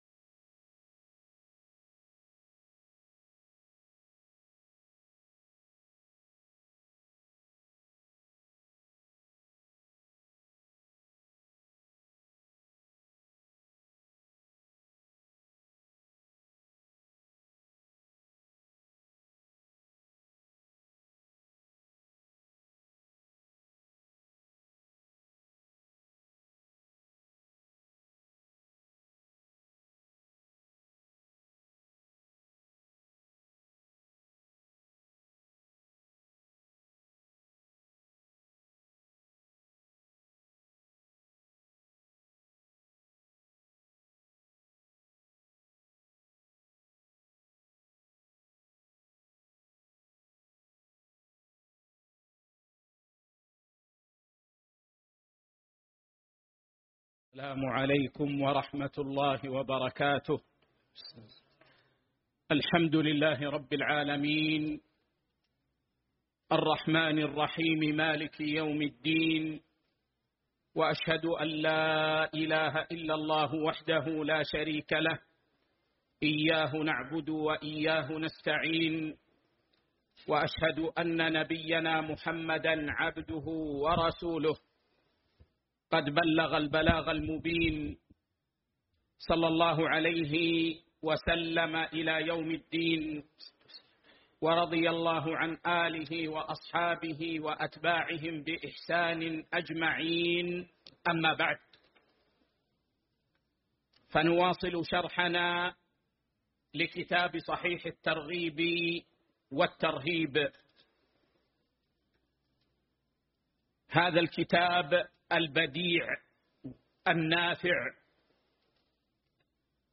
صحيح الترغيب والترهيب 1 شرح